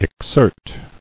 [ex·sert]